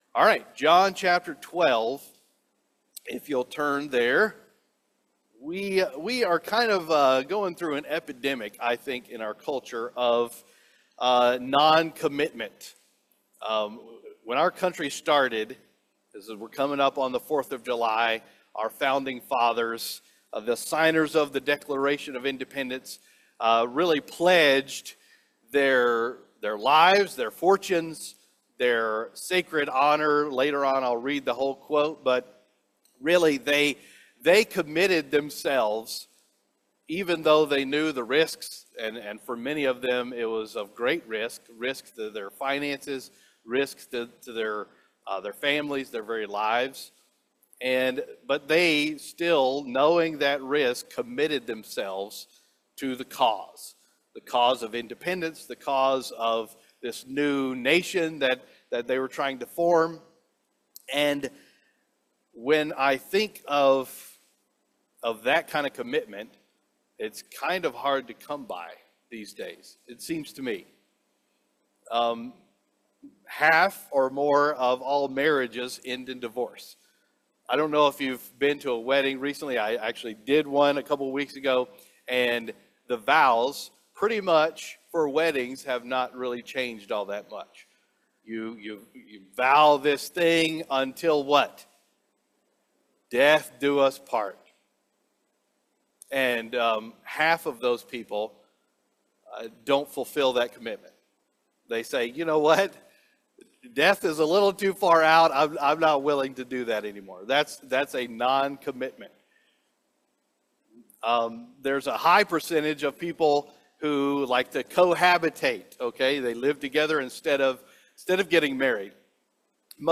Sermons - Meridian Baptist Church
Our latest Sunday morning worship service Messages from God’s word